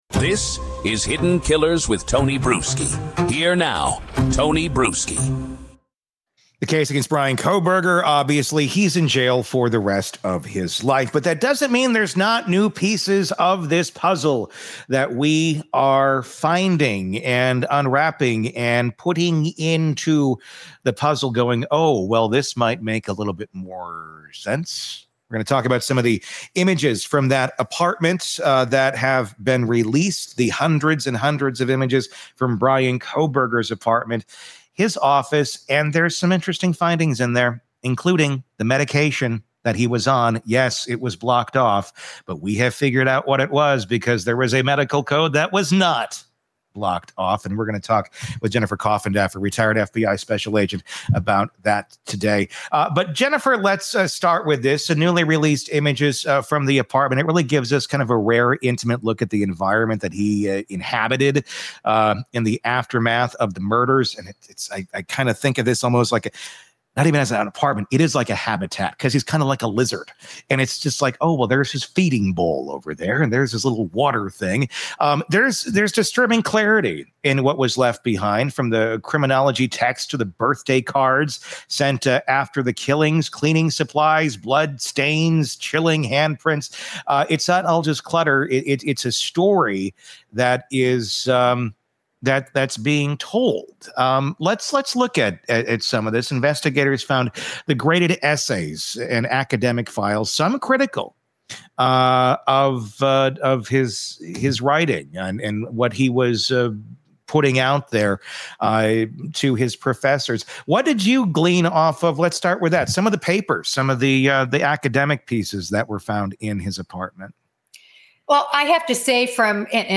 The conversation looks beyond “clutter” and asks what story the photos actually tell in a true crime context, focusing on environment, behavior, and post-crime habits rather than speculation.